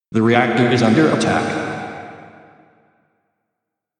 I just found a pretty nice text-to-speech that has a really great voice that sounds perfect for broadcast-like recording :smiley:
I changed the voice for one of them to see what it would sound like with a deeper voice saying it and another one sounded a little glitchy, but I liked the way it sounded :stuck_out_tongue: (It sounded like the voice was coming from the reactor itself and that the glitch was coming from the hits on the reactor.)